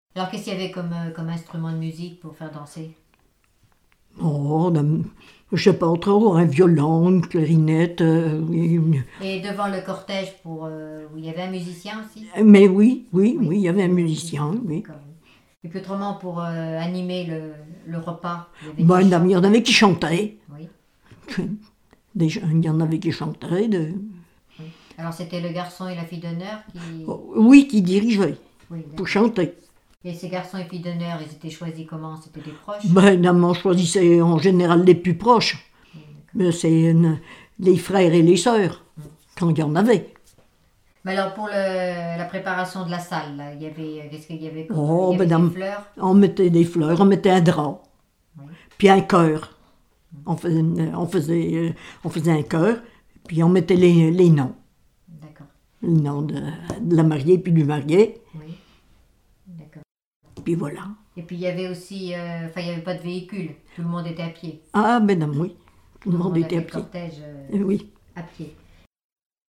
Enquête Arexcpo en Vendée-GAL Pays Sud-Vendée
Catégorie Témoignage